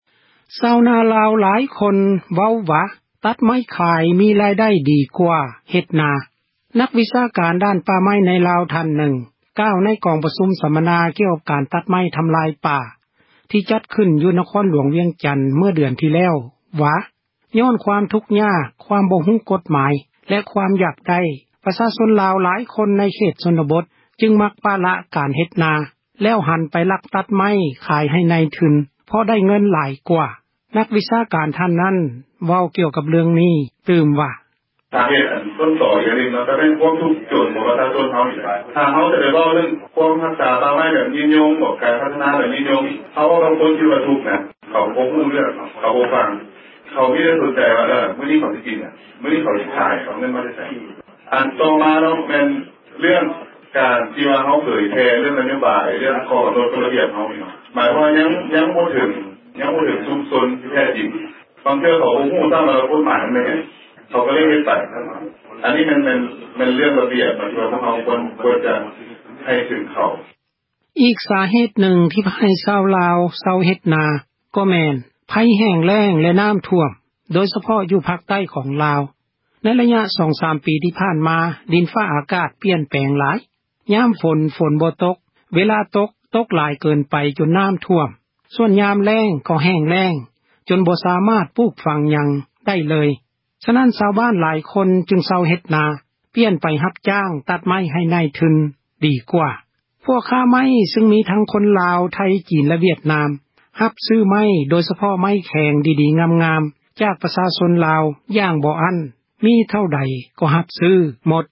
ນັກວິຊາການ ດ້ານ ປ່າໄມ້ ໃນລາວ ທ່ານນຶ່ງ ກ່າວ ໃນ ກອງປະຊຸມ ສັມມະນາ ກ່ຽວກັບ ການ ຕັດໄມ້ ທຳຣາຍປ່າ ທີ່ຈັດຂຶ້ນ ຢູ່ ນະຄອນ ຫລວງວຽງຈັນ ເມື່ອເດືອນ ທີ່ແລ້ວວ່າ ຍ້ອນ ຄວາມທຸກຍາກ ຄວາມບໍ່ຮູ້ ກົດໝາຍ ແລະ ຄວາມຢາກໄດ້ ປະຊາຊົນ ລາວ ຫລາຍຄົນ ໃນເຂດ ຊົນນະບົດ ຈຶ່ງມັກ ປະລະ ການເຮັດນາ ແລ້ວຫັນໄປ ລັກຕັດໄມ້ ຂາຍ ໃຫ້ນາຍທຶນ ເພາະໄດ້ ເງິນ ຫຼາຍກວ່າ. ນັກ ວິຊາການ ທ່ານນັ້ນ ເວົ້າວ່າ: